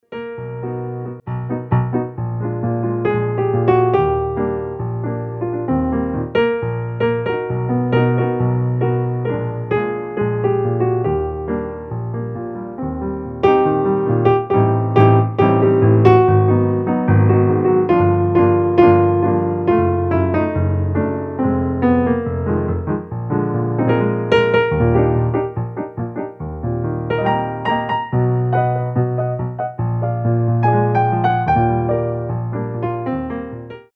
entertainment pianists